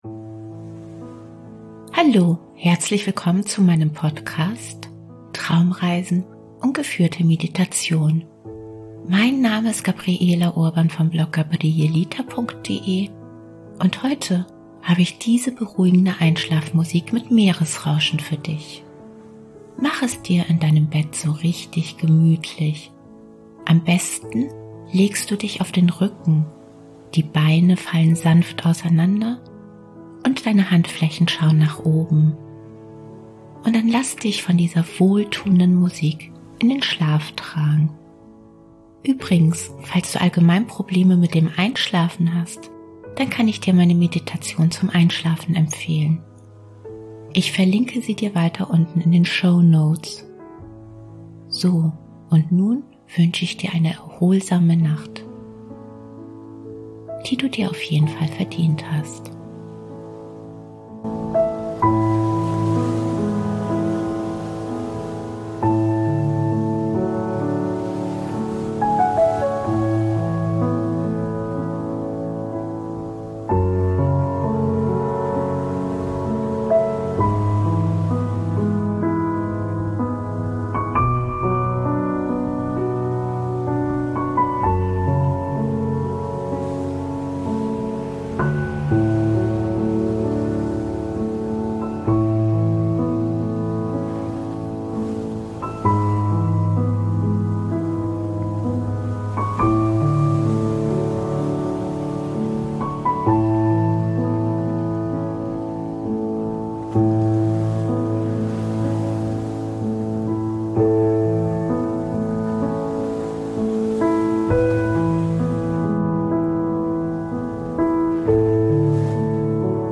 Beruhigende Einschlafmusik Meeresrauschen
Und dann lass dich von dieser wohltuenden Musik in den Schlaf tragen.